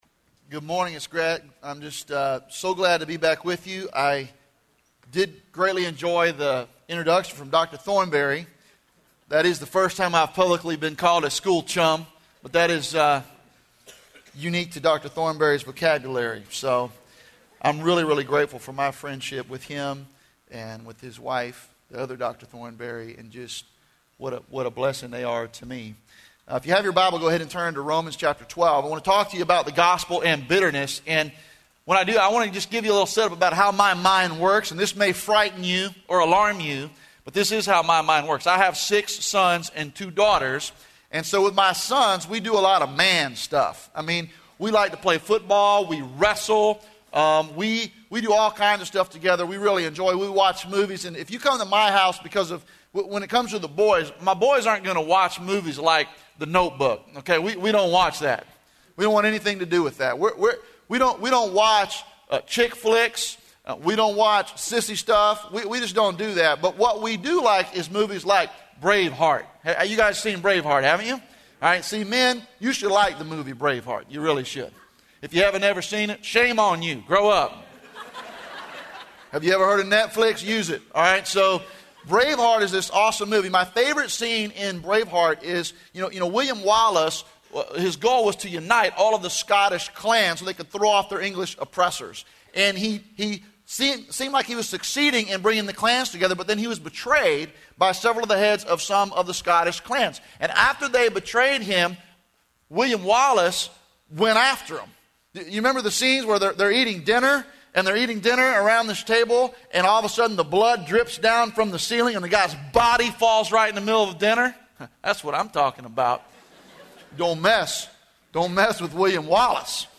Faith in Practice Chapel
Chapels